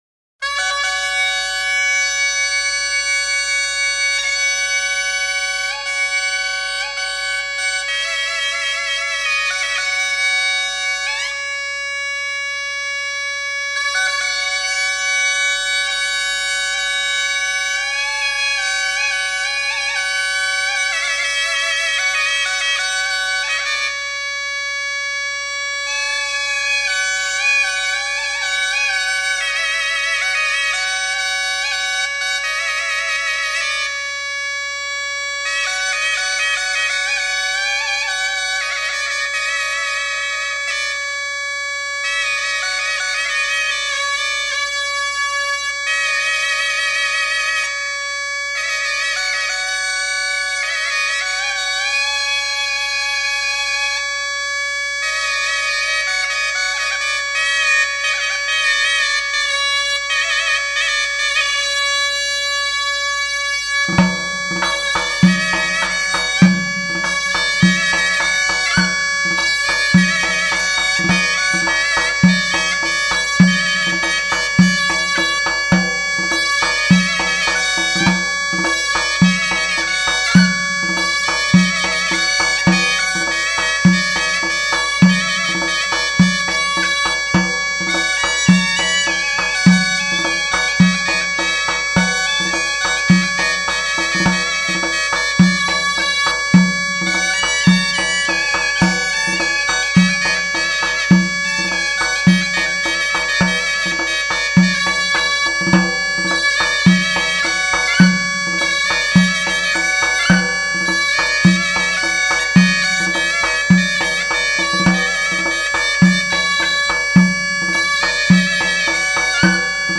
The warm, nasal tones of the duduk played by